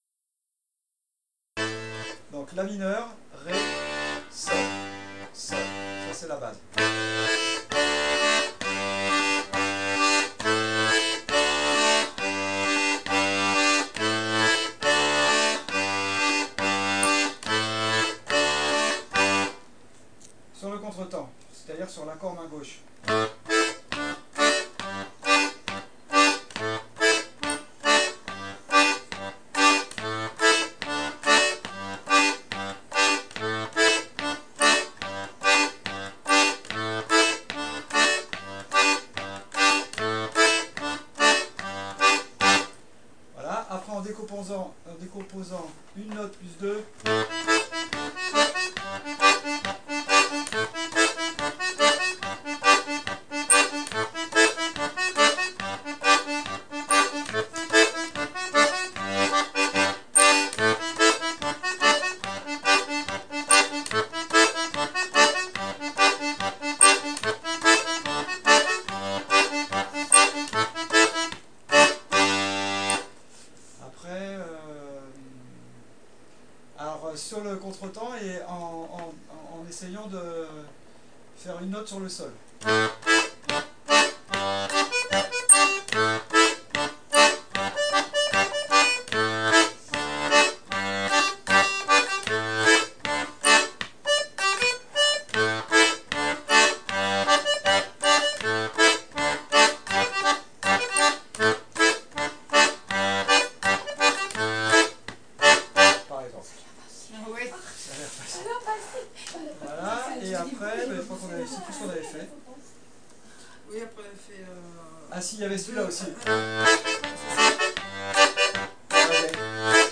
l'atelier d'accordéon diatonique
1) les rythmes à 8 temps (4X2, le colporteur);
pour travailler: rythmique sur la grille "Lam, Rém, Sol" comment varier la main droite: